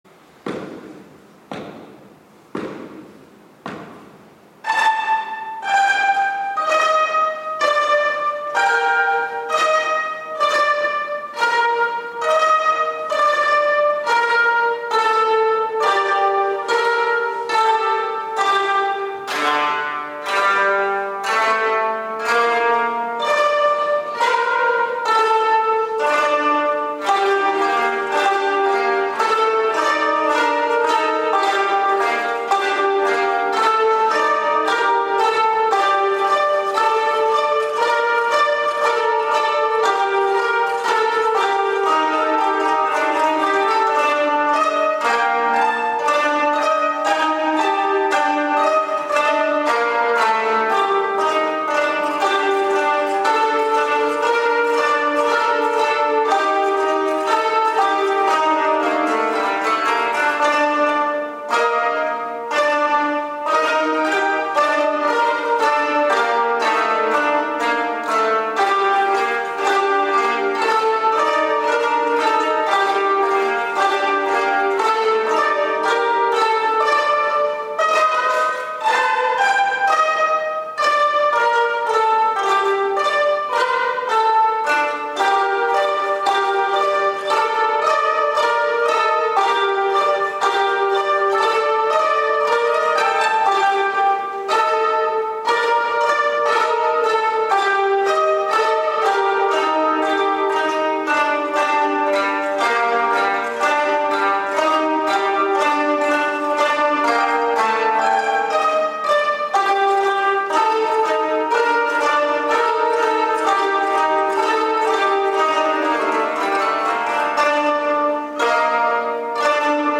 記念式典
１　お琴披露（６年生）